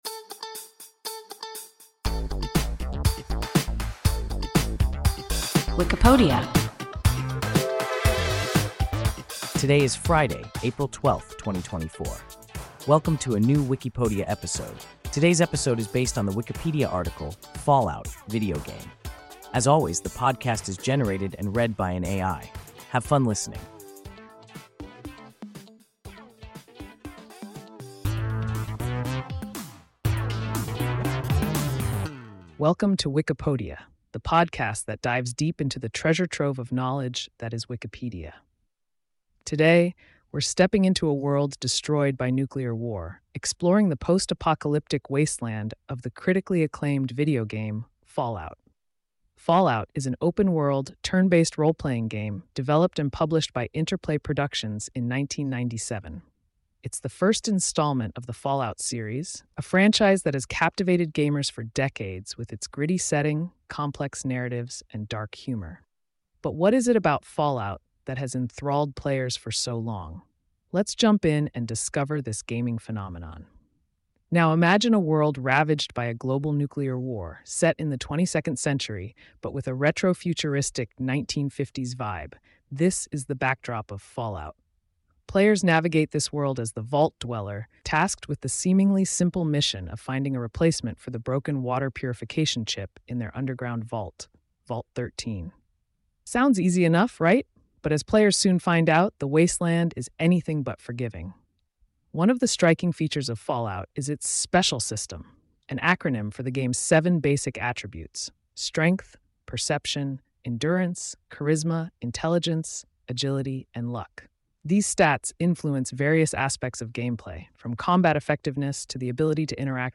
Fallout (video game) – WIKIPODIA – ein KI Podcast